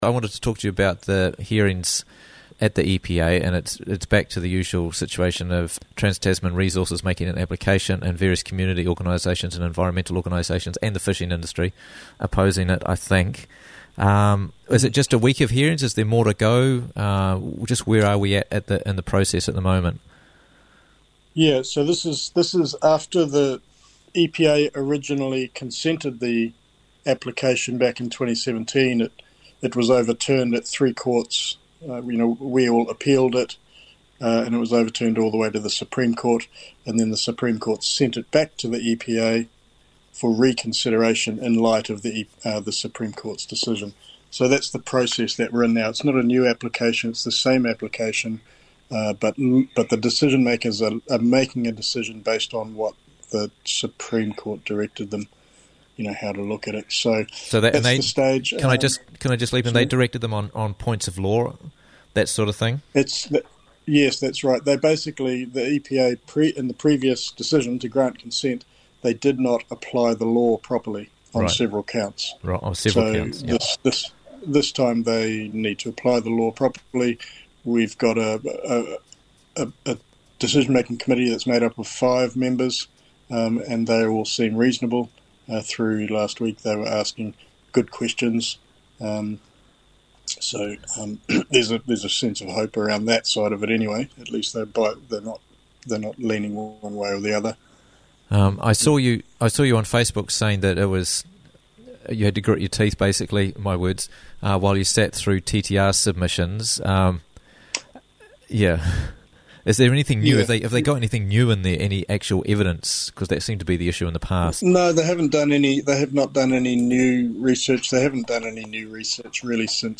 The Latest on the Seabed Mining Hearings - Interviews from the Raglan Morning Show